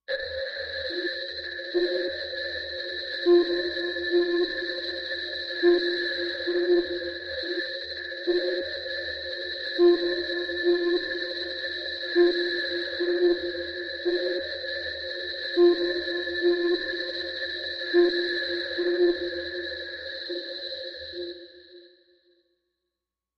Threat Probe, Machine, Space Probe, Sonar, Searchlight, Radio Transmission